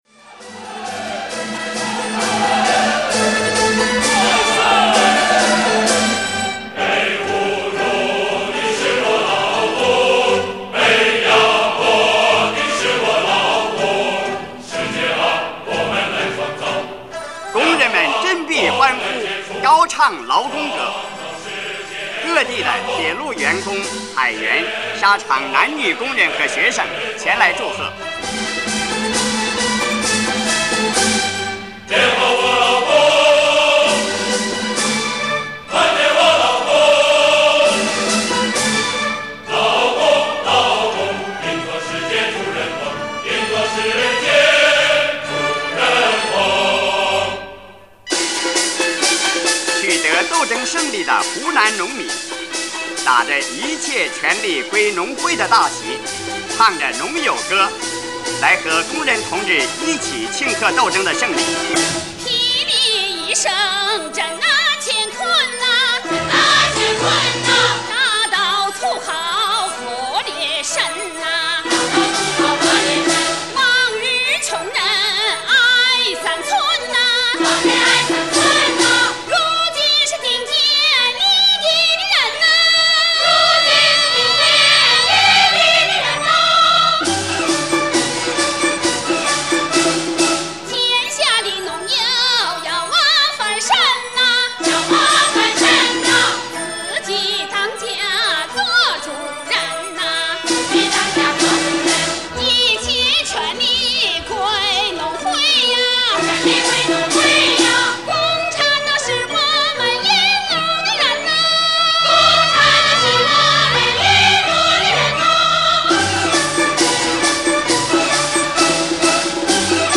全剧录音
这是一部以歌唱、舞蹈和戏剧相结合的综合性艺术形式